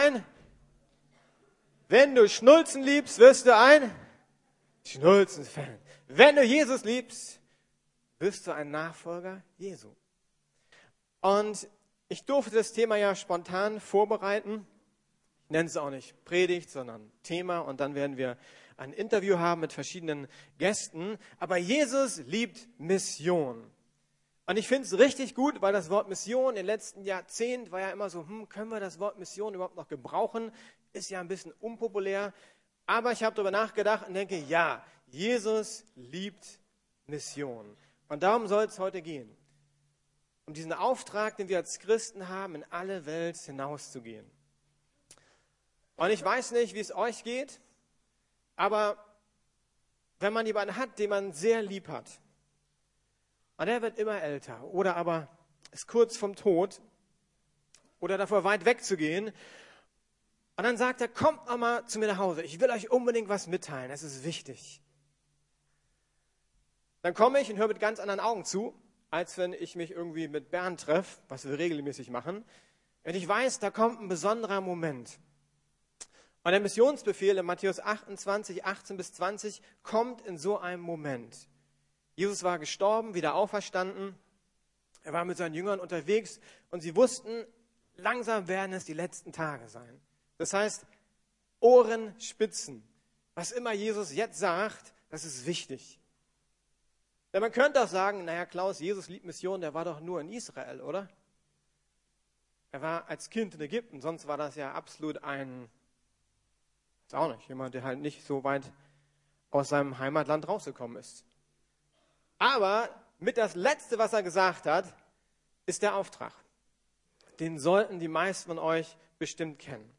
Mit Gott auf dem Weg in die Freiheit ~ Predigten der LUKAS GEMEINDE Podcast